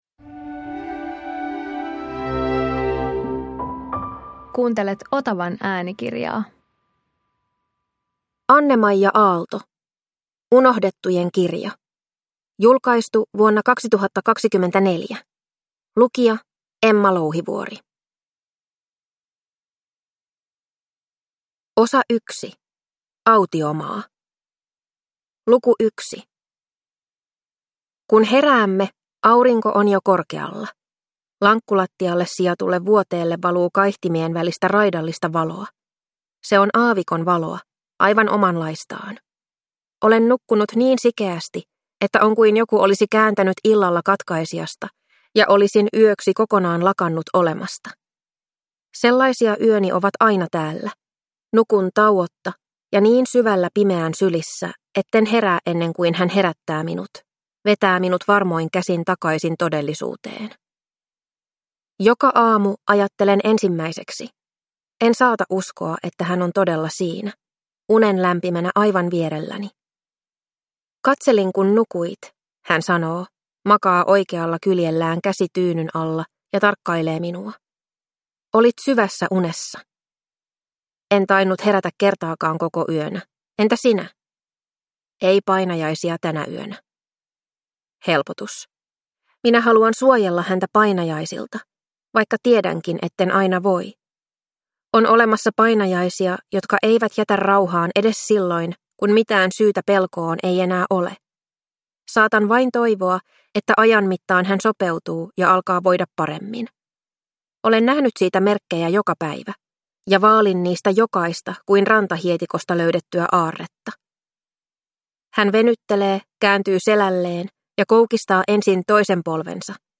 Unohdettujen kirja – Ljudbok